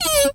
mouse_emote_07.wav